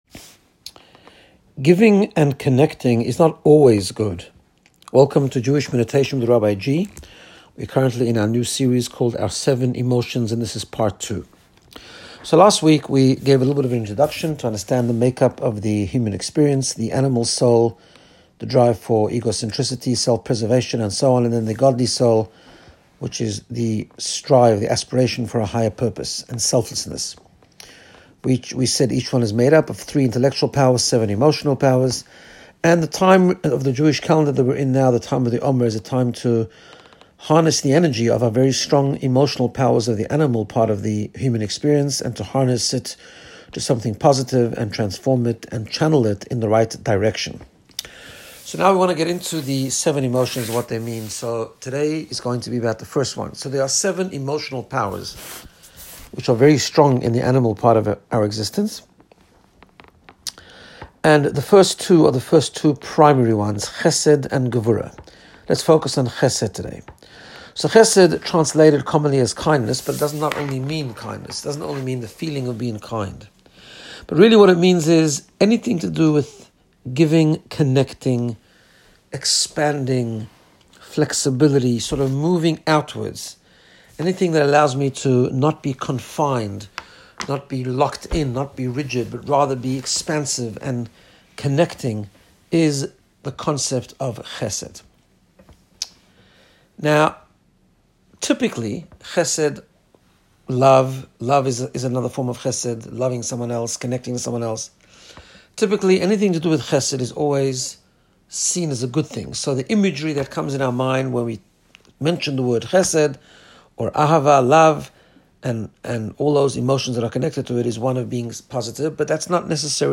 Guided Meditation to get you in the sapce of Personal Growth and Wellbeing.